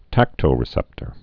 (tăktō-rĭ-sĕptər)